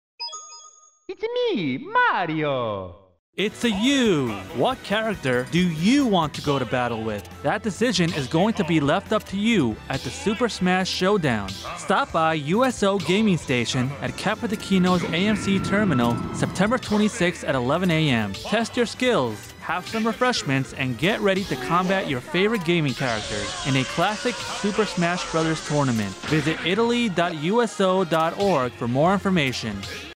tournamentgamingSuper Smash BrothersUSONSA Naples